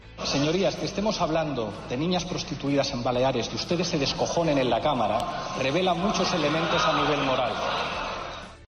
Declaraciones de Pablo Iglesias durante al sesión de control al gobierno